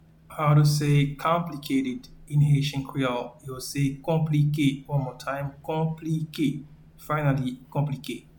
Pronunciation and Transcript:
Complicated-in-Haitian-Creole-Konplike.mp3